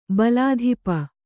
♪ balādhipa